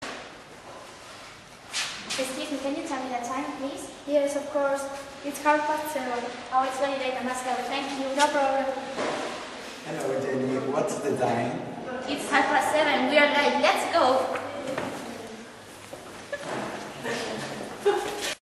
Everyday conversations
Dos chicas de pié una frente a otra en el pasillo de un instituto mantienen una conversación.